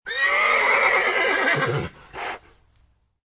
Descarga de Sonidos mp3 Gratis: caballo 29.